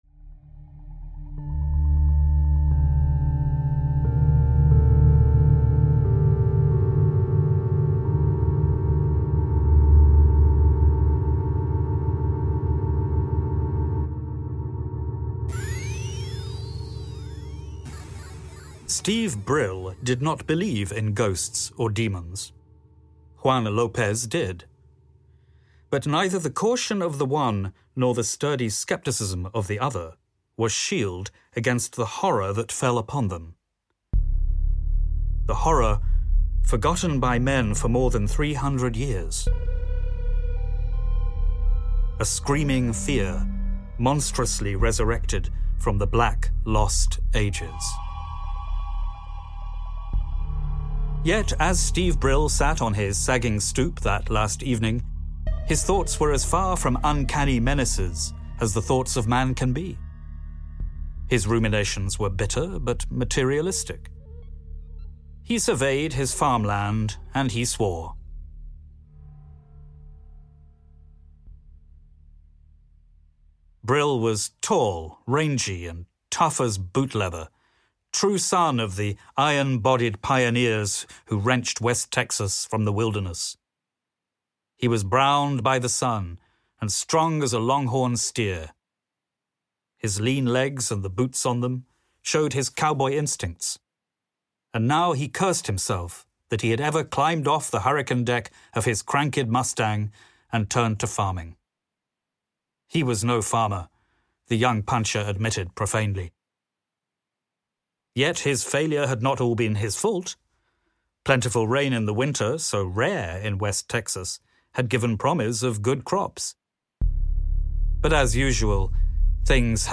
Audio knihaTouch of Death
Ukázka z knihy